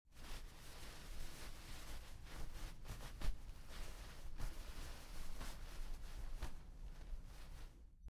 Download Cloth sound effect for free.
Cloth